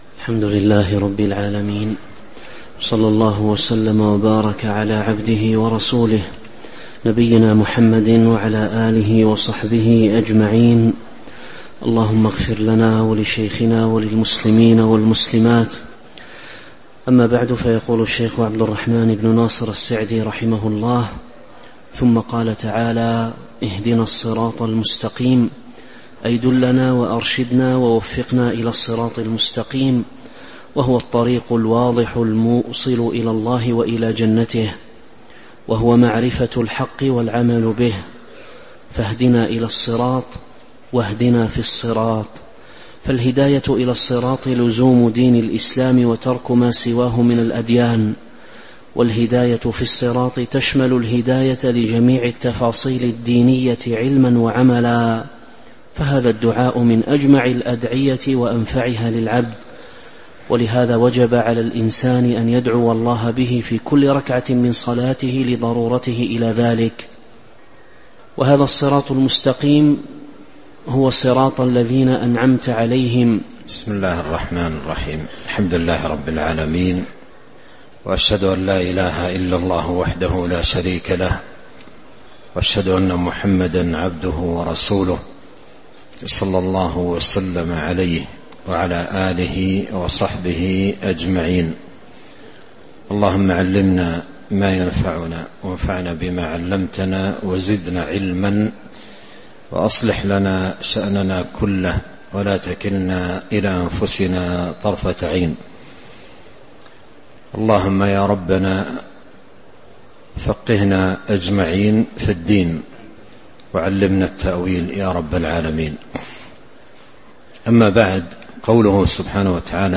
تاريخ النشر ١٦ ربيع الأول ١٤٤٦ هـ المكان: المسجد النبوي الشيخ